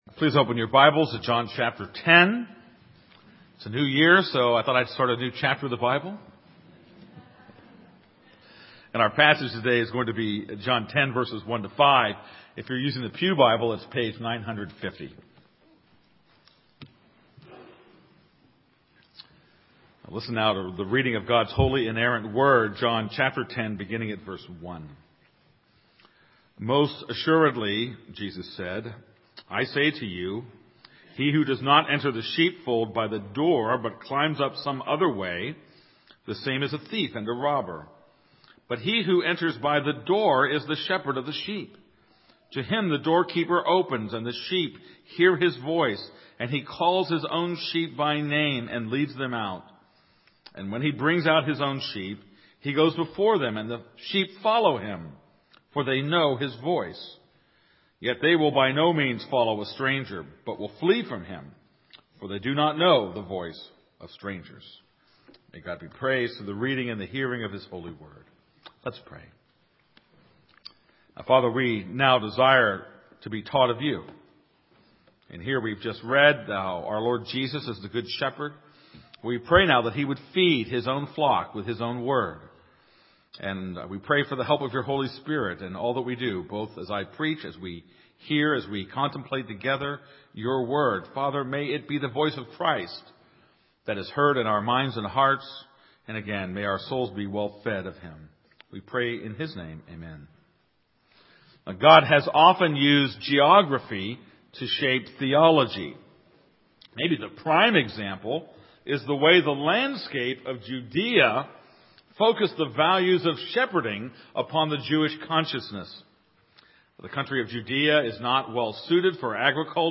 This is a sermon on John 10:1-5.